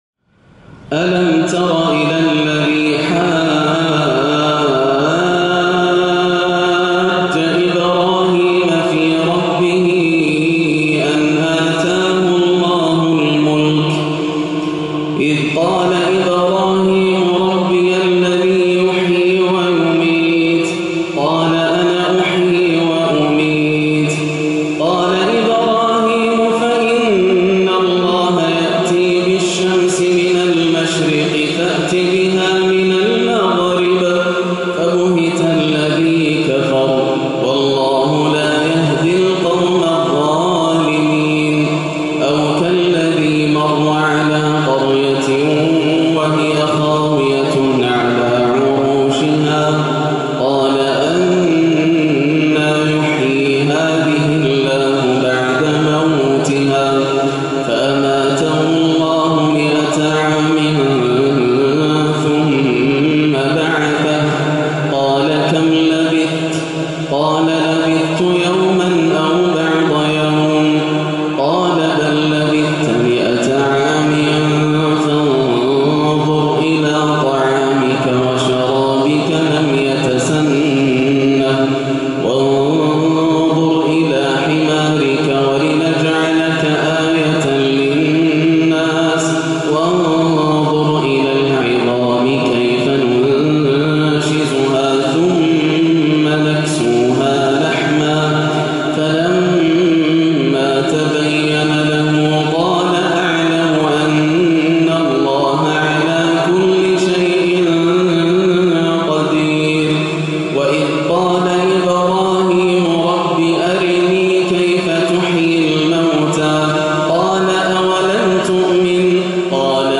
(فَبهِتَ الذِي كفُر) تلاوة خاشعة لما تيسر من سورة البقرة - 3-2-1437هـ > عام 1437 > الفروض - تلاوات ياسر الدوسري